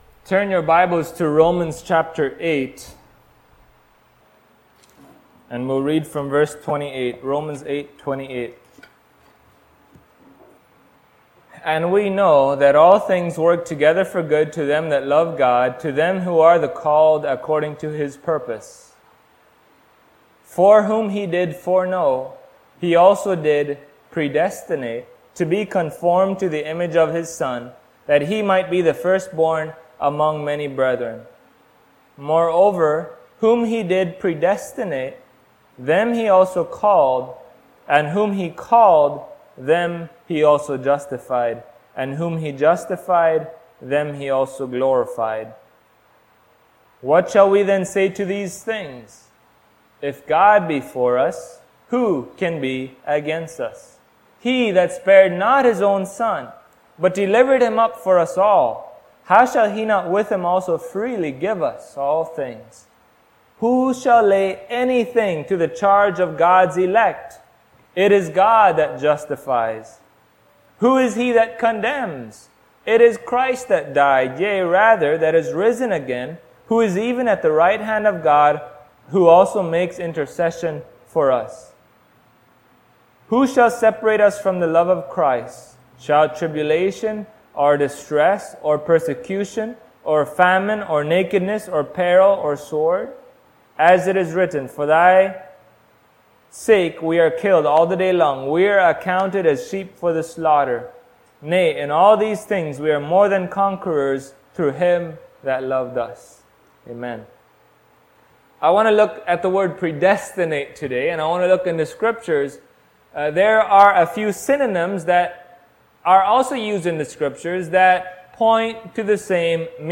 Passage: Romans 8:29 Service Type: Sunday Morning Topics